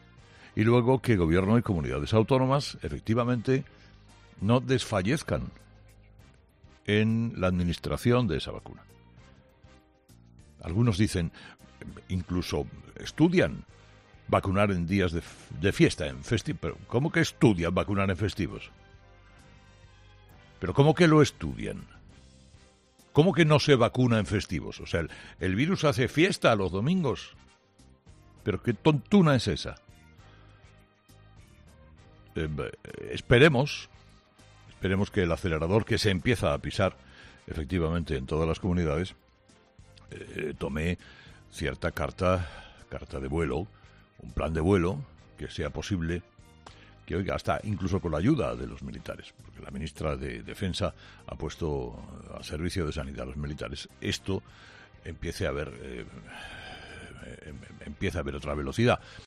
El periodista de la cadena COPE, Carlos Herrera, ha arrancado este miércoles Día de Reyes con un mensaje a todas las comunidades autónomas y autoridades implicadas por los datos de vacunación que España está registrando en las últimas horas.